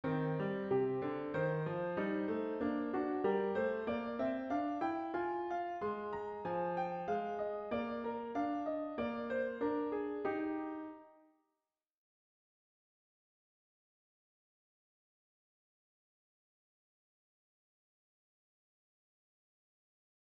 Trois voix sont entrées en jeu. Les deux mesures qui vont suivre donnent alors à entendre un moment plus libre.